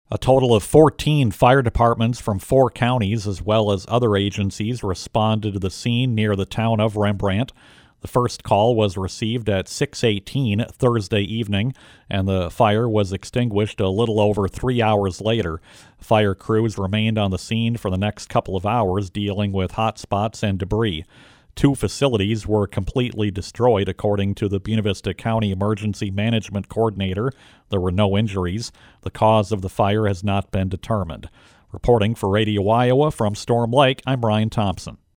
filed this report: